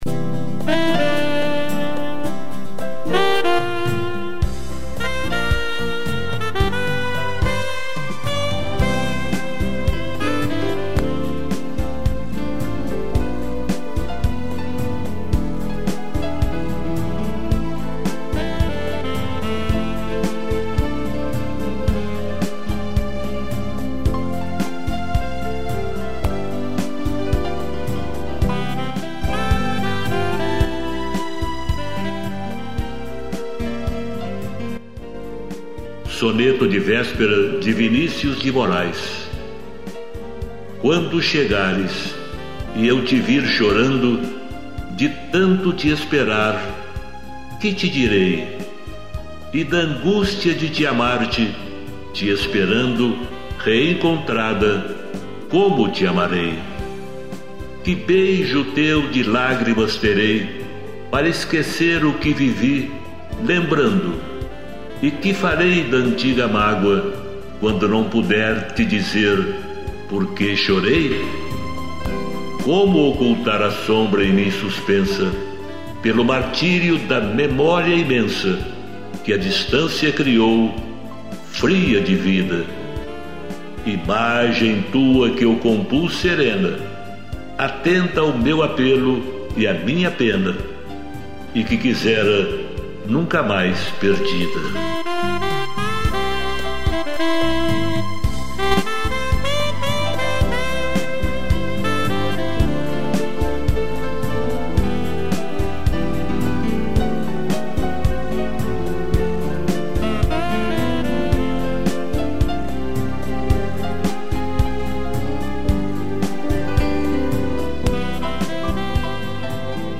piano, strings e sax